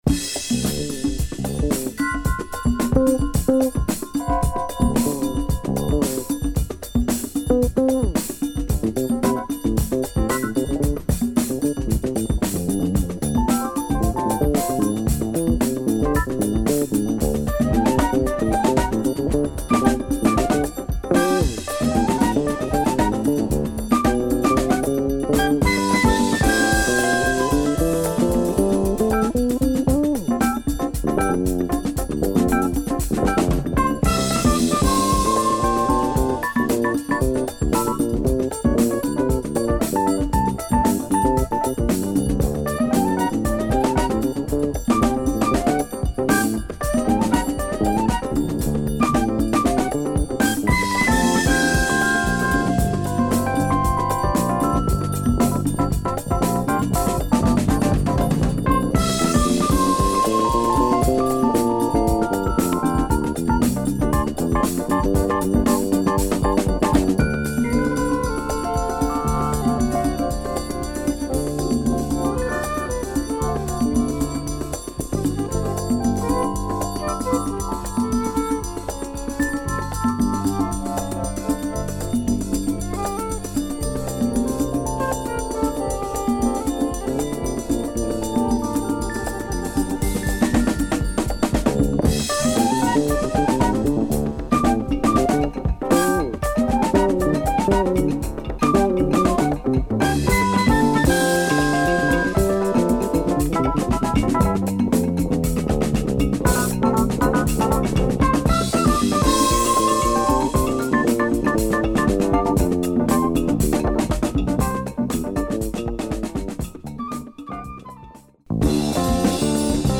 Killer jazz groove from Catalunya, Spain
a tremendous jazz funk tune.